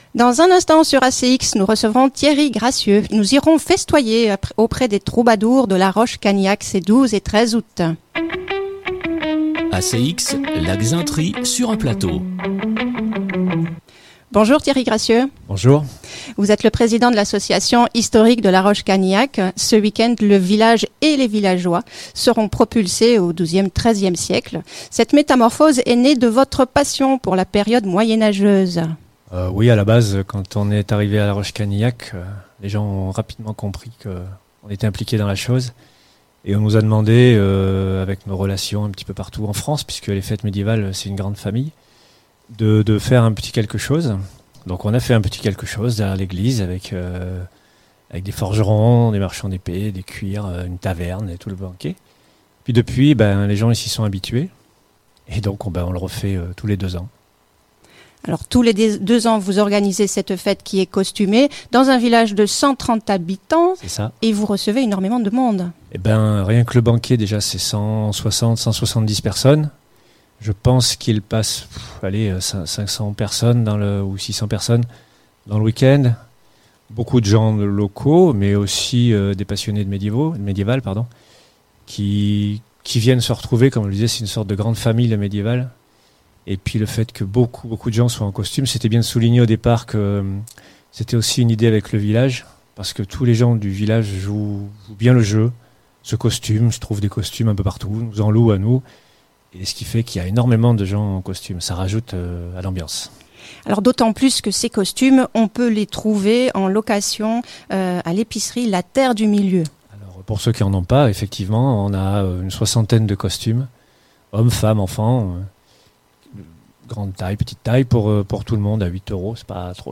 Fête médiévale – La roche canillac1.
Fete-medievale-La-roche-canillac1.mp3